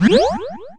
SFX魔法状态音效下载
SFX音效